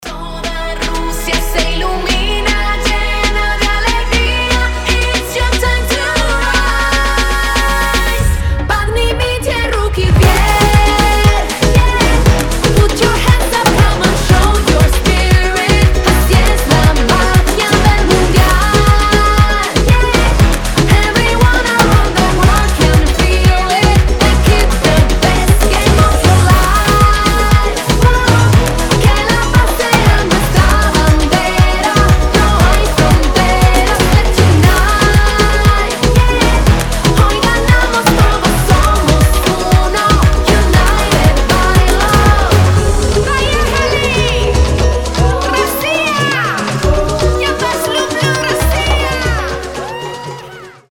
поп
громкие
зажигательные
заводные
красивый женский вокал
Latin Pop
гимны